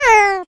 animalia_cat_hurt.ogg